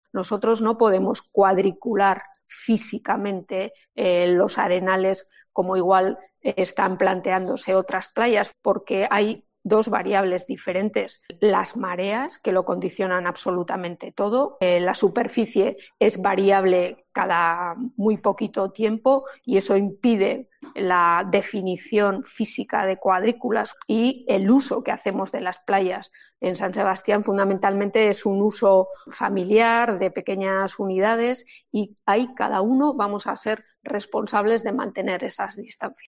Marisol Garmendia, edil de Espacios Públicos.